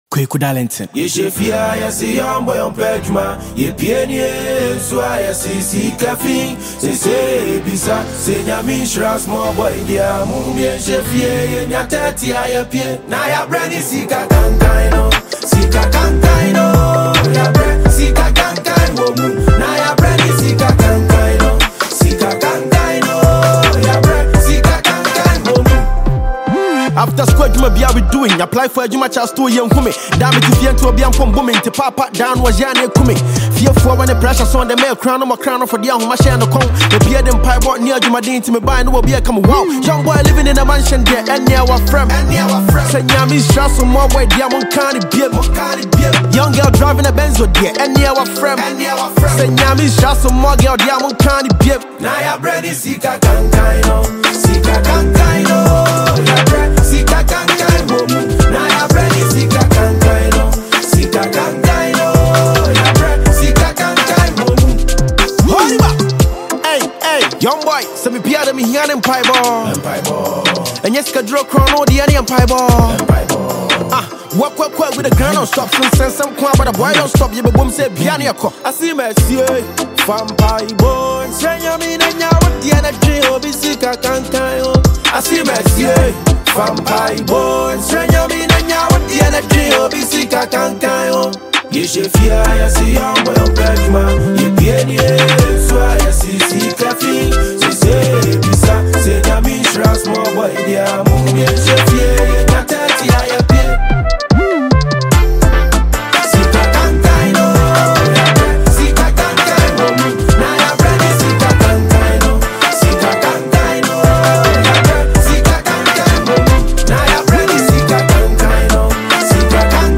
Ghana Music Music
Ghanaian rapper and singer
catchy single